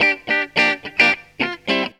GTR 63 GM.wav